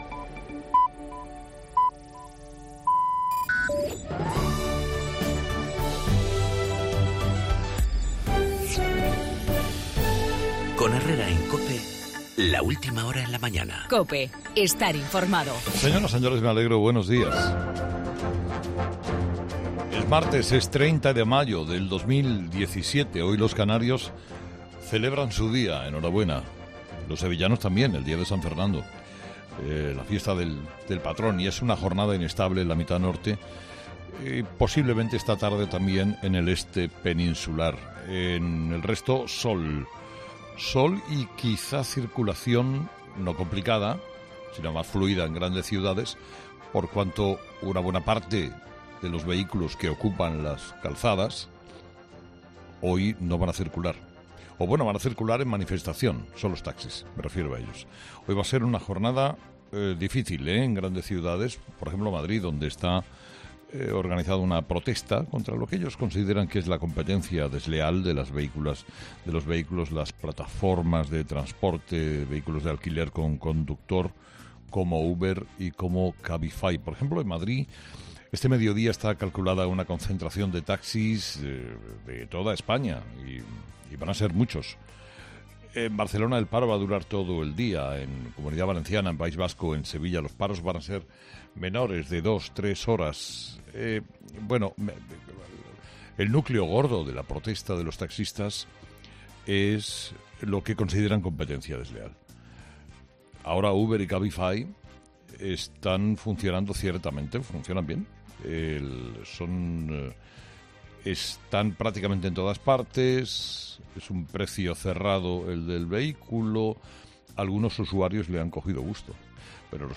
Monólogo de las 8 de Herrera